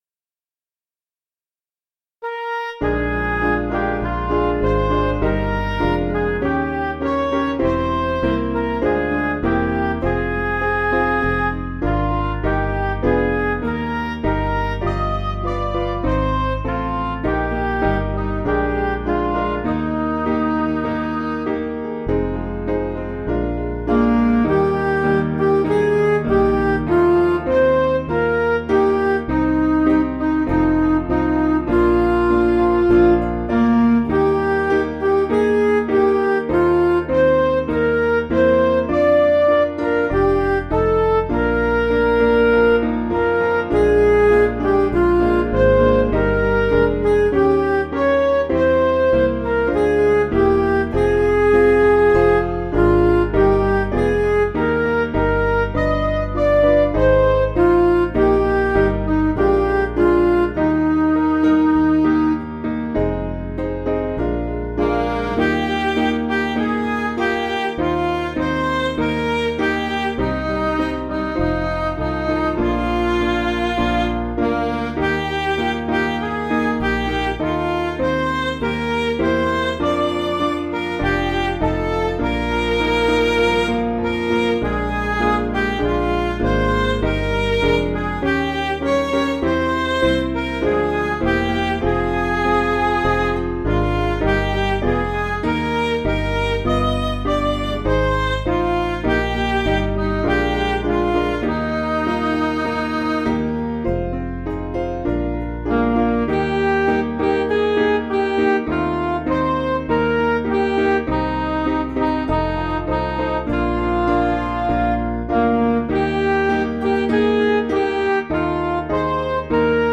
(CM)   4/Eb
Midi